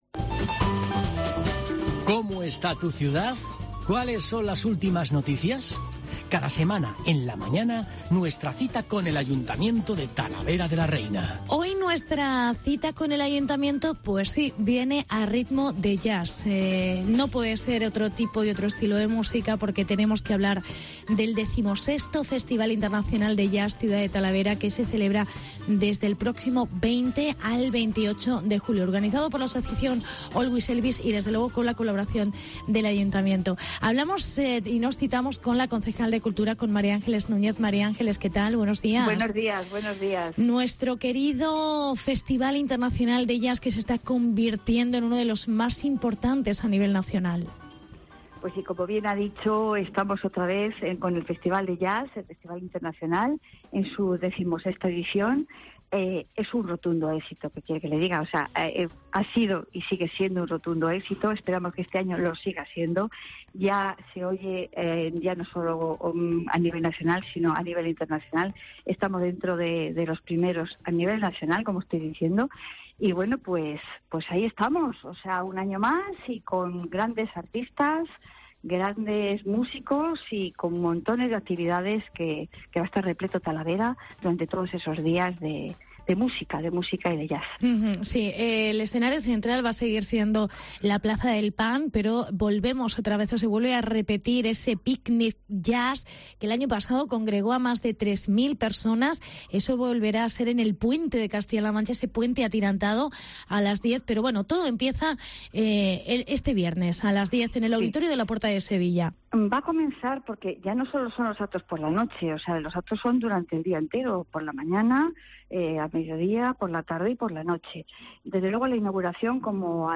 Entrevista con la concejal Mª Ángeles Núñez